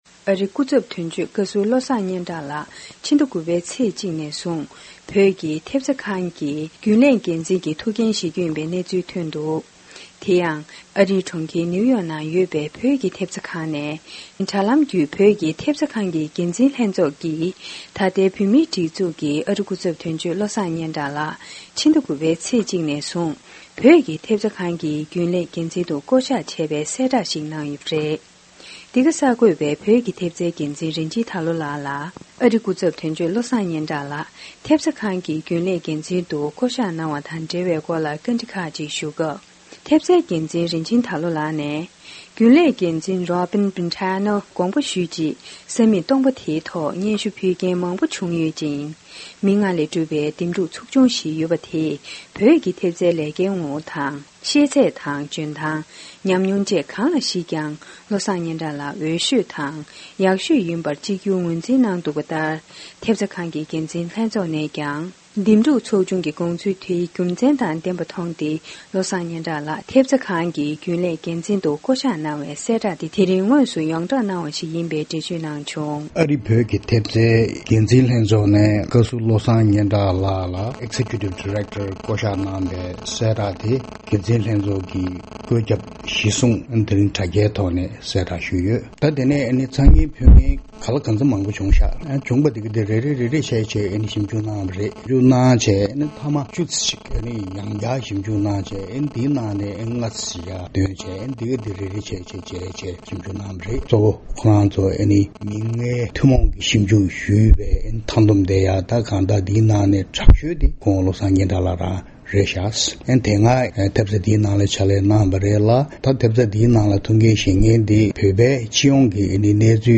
སྤེལ་བའི་གནས་ཚུལ་ཞིག་གསན་གྱི་རེད།